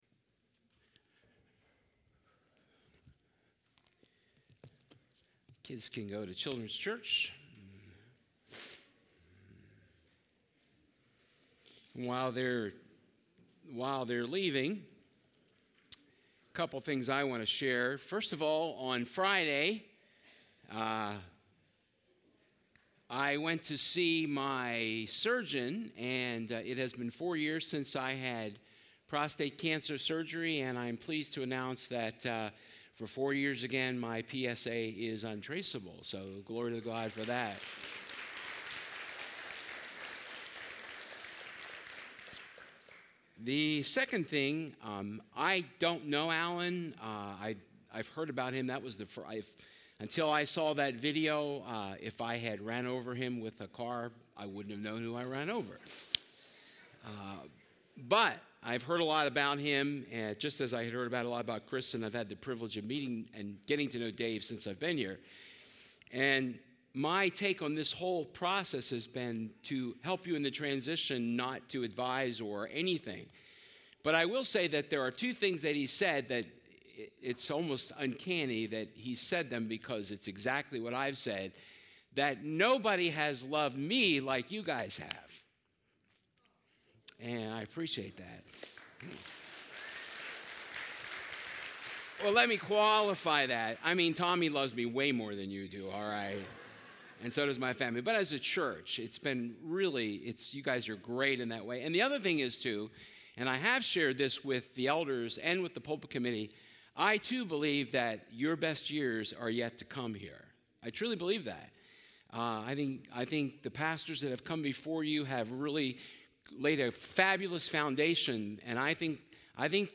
2 Corinthians 12 Service Type: Sunday Service We are a broken people who live in a broken world « June 21